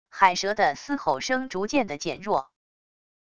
海蛇的嘶吼声逐渐的减弱wav音频